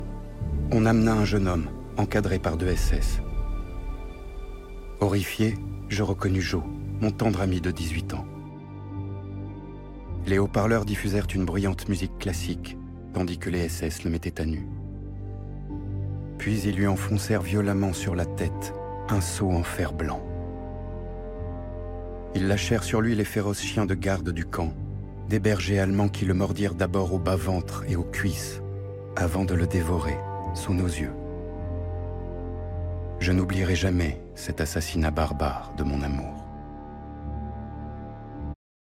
Voix Off Docu Historique "Triangle Rose" 39-45 - France 5